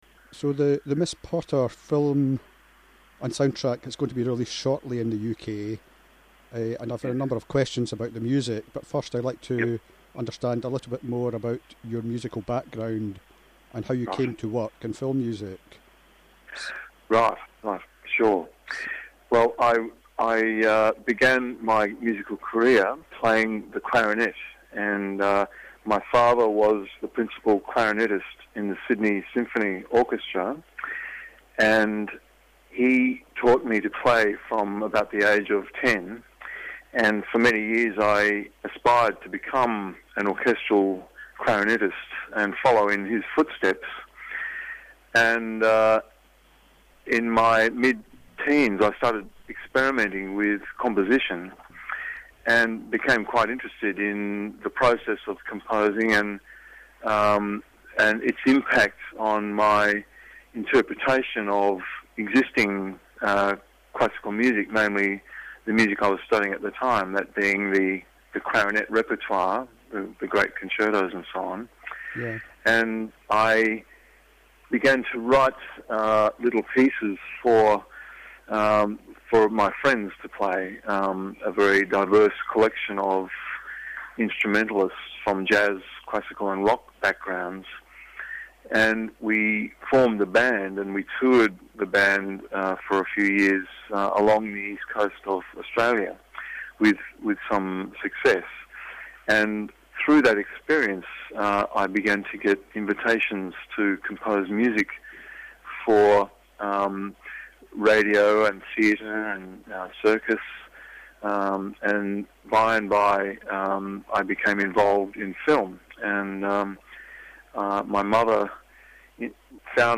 Nigel Westlake - interview with composer focusing on Miss Potter.
nigel-westlake-miss-potter-interview.mp3